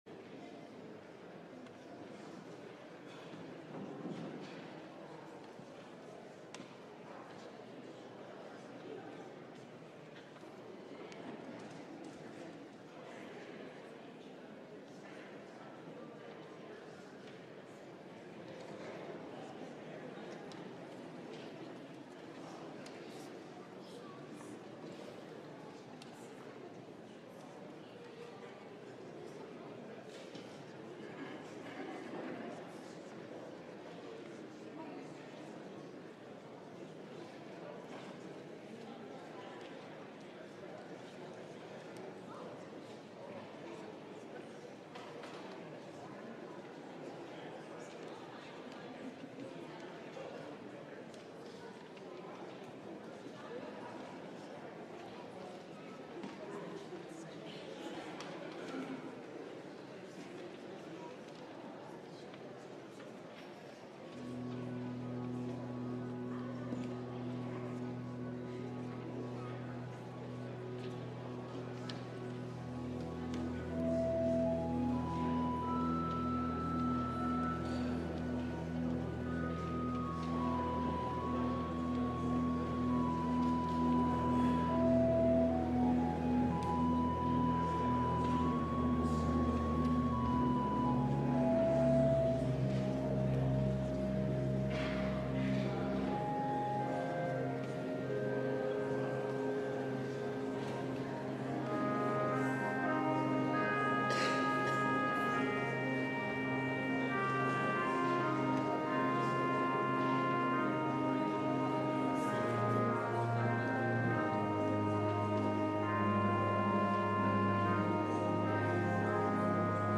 LIVE Morning Worship Service - Faith 101: Creation Theology
Congregational singing—of both traditional hymns and newer ones—is typically supported by our pipe organ.